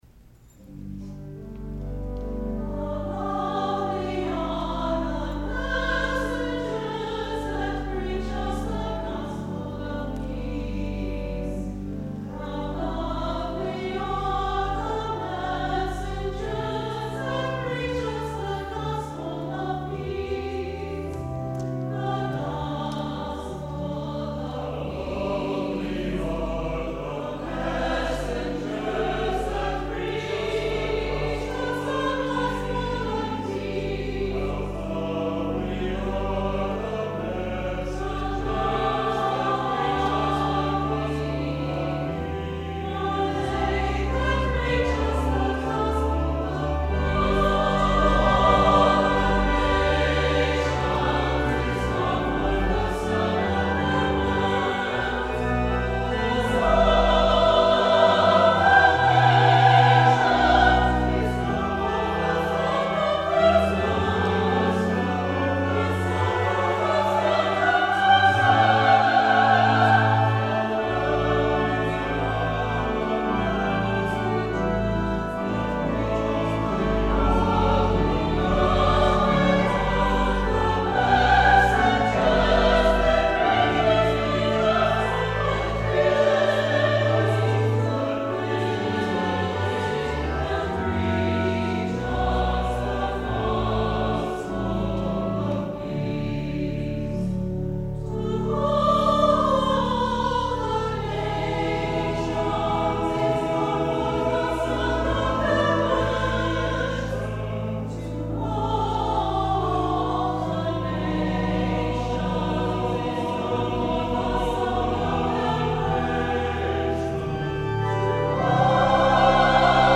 Performer:  Chancel Choir